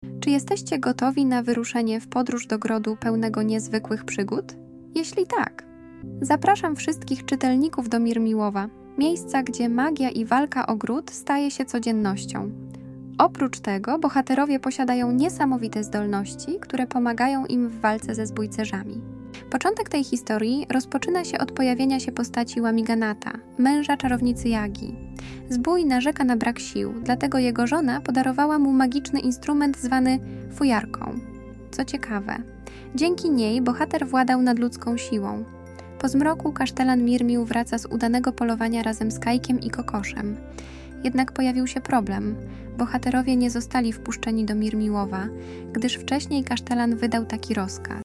• Szata graficzna i muzyka zachęci Twoje dziecko do zapoznania się z treścią opracowania.
Kajko i Kokosz - Prezentacja multimedialna, audiobook, e -book